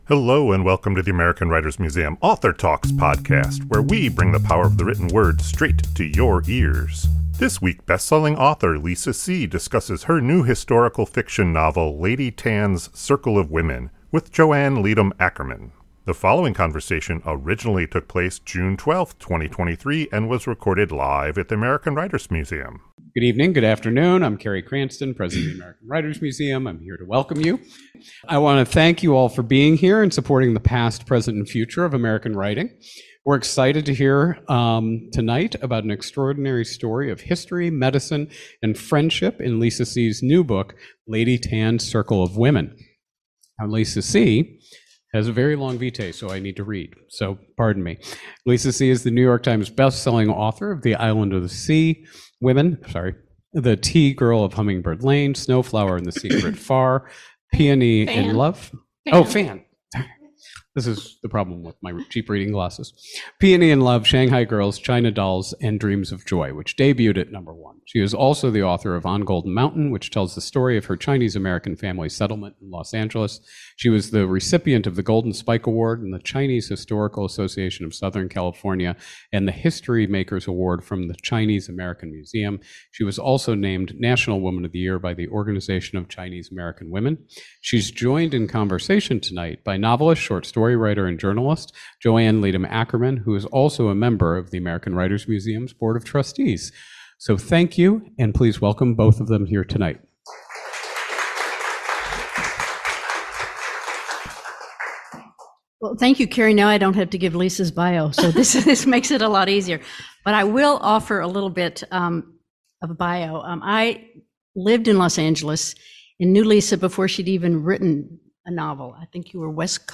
The following conversation originally took place June 12, 2023 and was recorded live at the American Writers Museum.